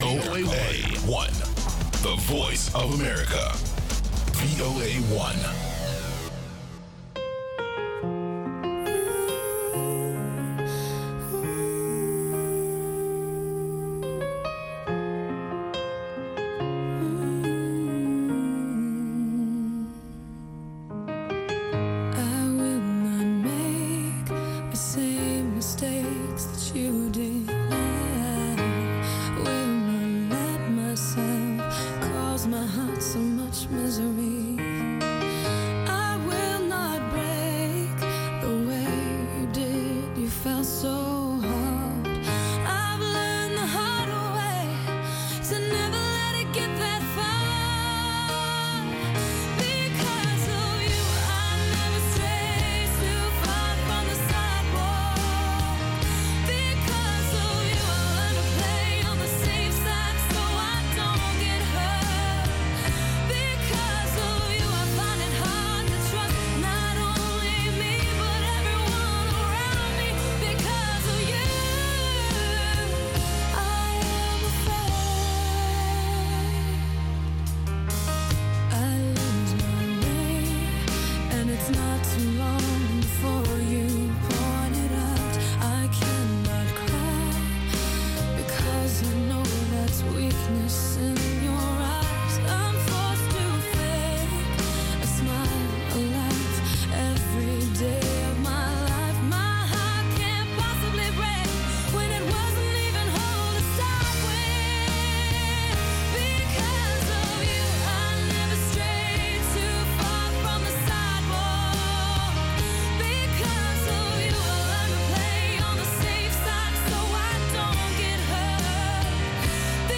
You’ll also hear Classic Soul from legendary musicians who have inspired a the new generation of groundbreaking artists.